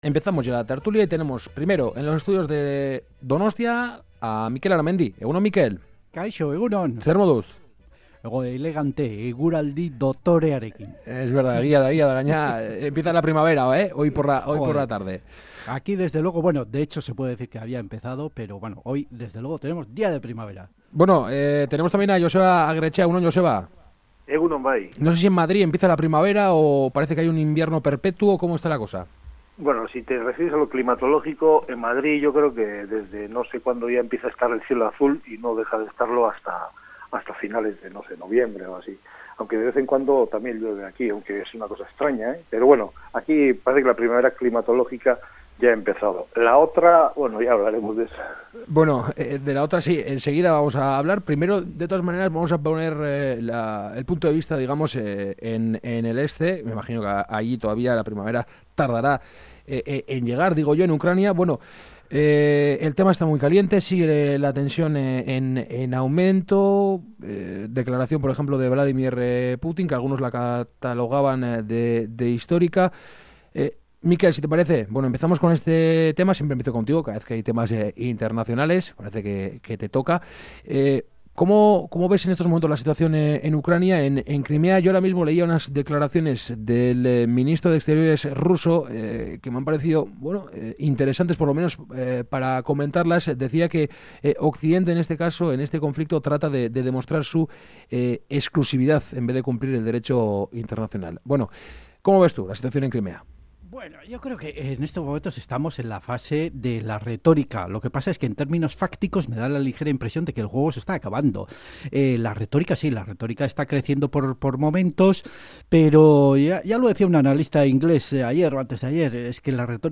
La tertulia de Kale Gorrian